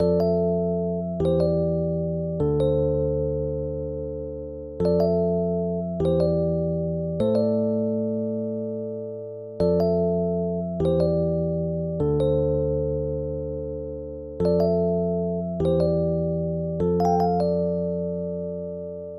标签： 100 bpm RnB Loops Piano Loops 3.23 MB wav Key : F
声道立体声